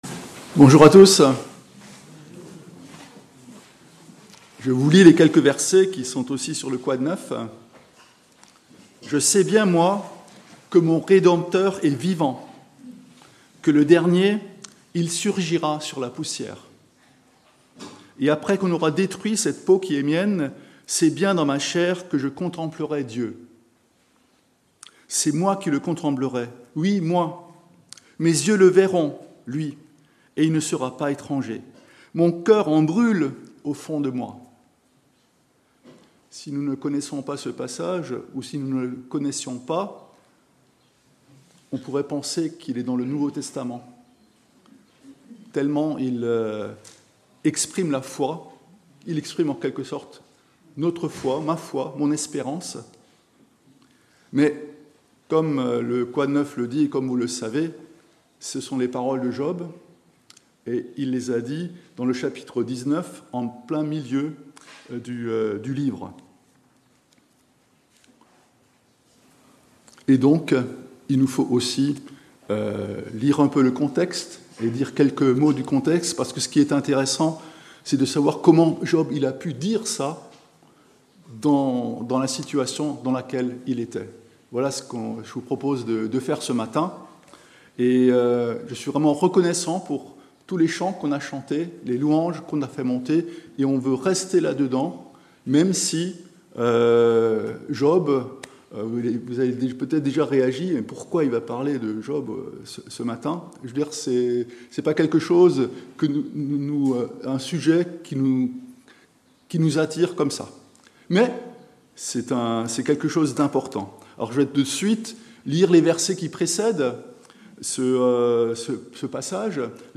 Culte du dimanche 27 juillet 2025 – Église de La Bonne Nouvelle